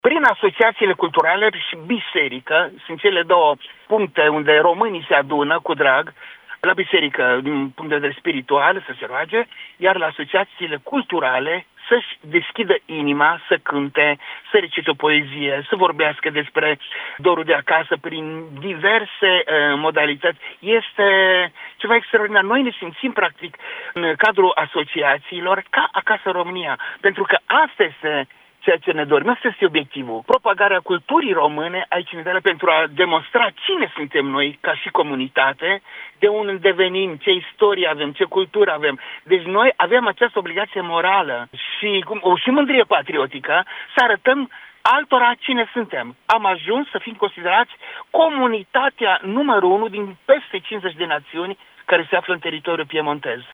declarație